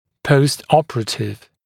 [ˌpəustˈɔpərətɪv][ˌпоустˈопэрэтив]послеоперационный